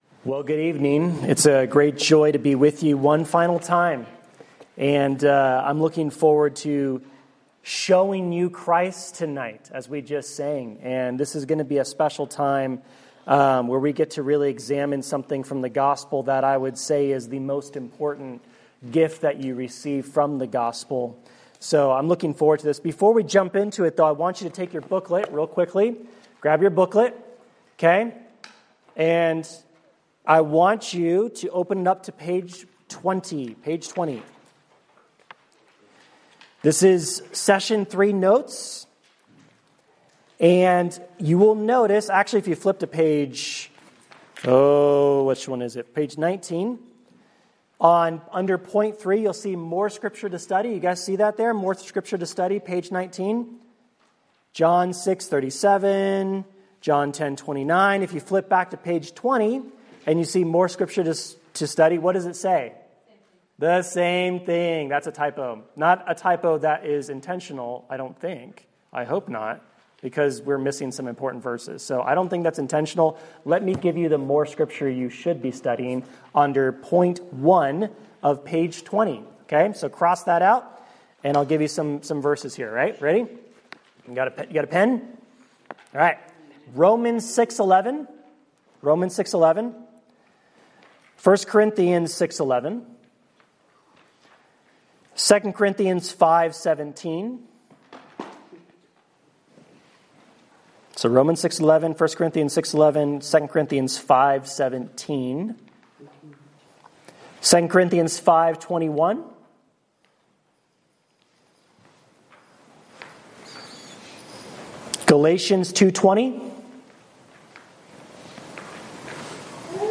Date: Jan 24, 2026 Series: The Gospel on Tap (Winter Retreat 2026) Grouping: Student Ministries More: Download MP3